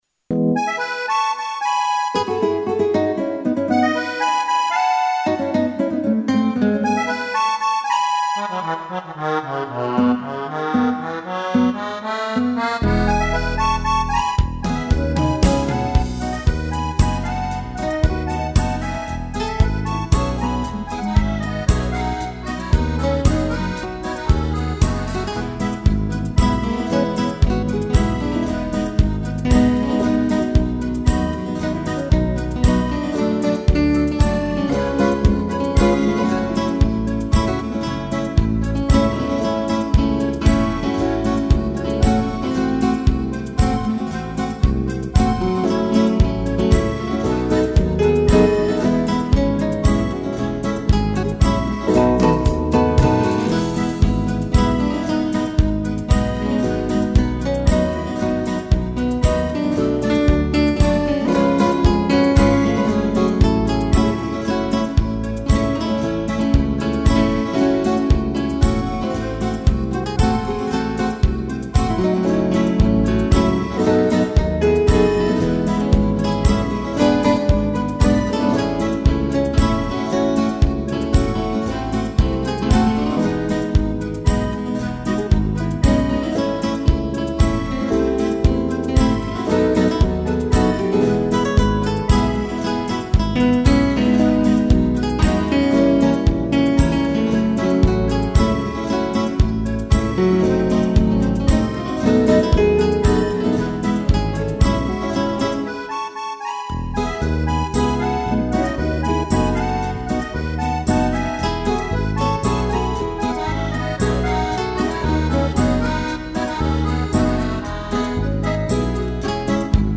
violão
(instrumental)